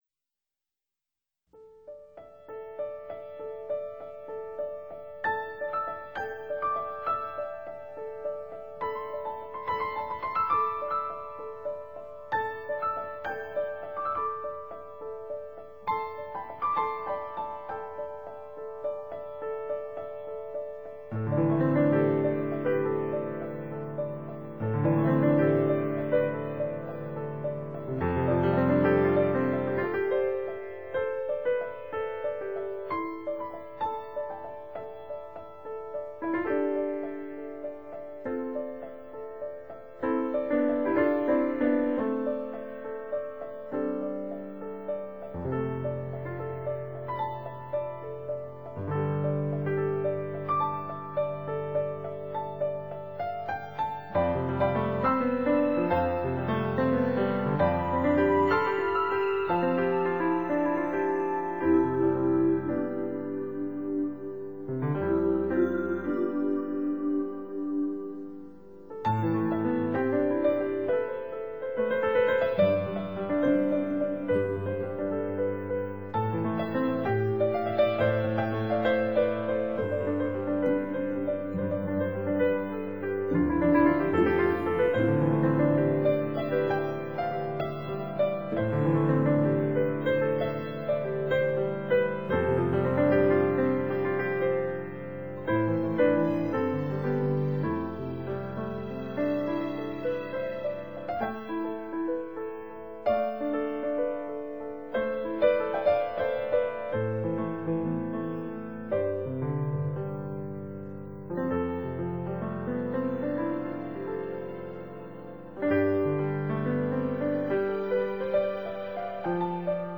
钢琴单曲